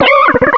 cry_not_simipour.aif